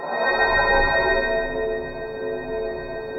ATMOPAD05 -LR.wav